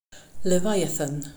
pronunciation)